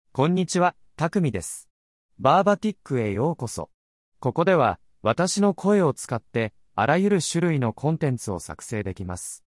Takumi — Male Japanese AI voice
Takumi is a male AI voice for Japanese.
Voice sample
Male
Takumi delivers clear pronunciation with authentic Japanese intonation, making your content sound professionally produced.